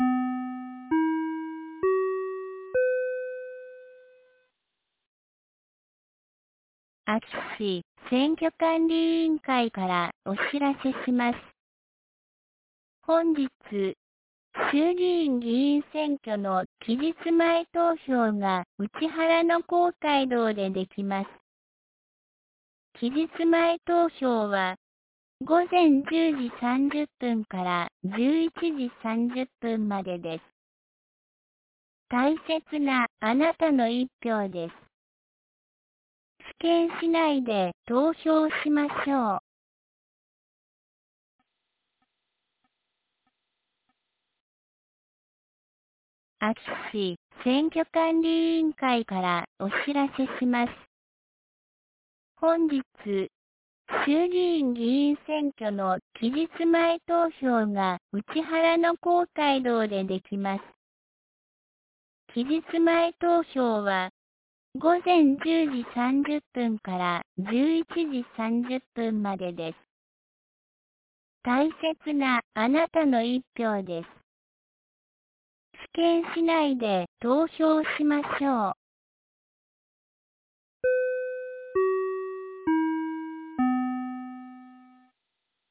2024年10月24日 10時01分に、安芸市より井ノ口へ放送がありました。